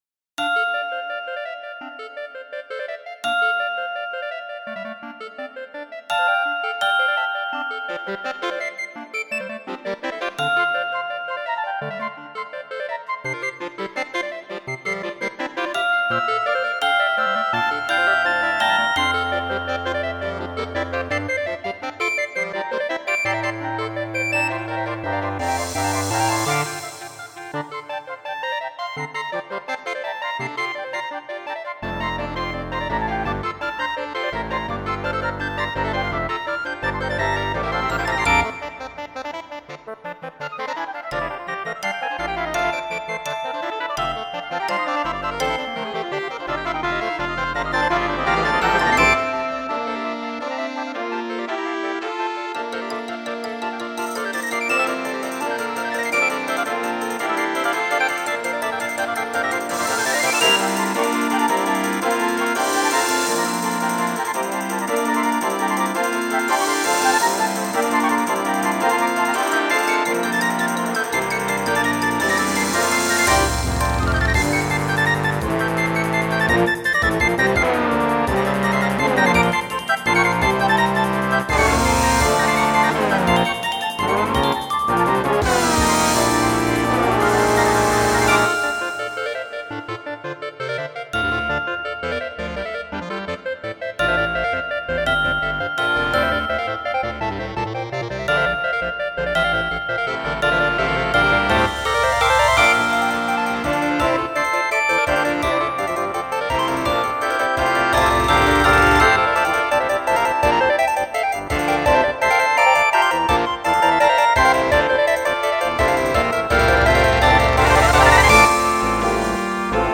for wind ensemble
Movement II: Eos (midi realization)
In the second movement, Eos has arrived, and the vigor of life is in full swing.